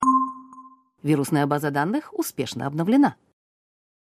В коллекции – системные оповещения, сигналы сканирования и другие характерные аудиофрагменты длиной от 1 до 6 секунд.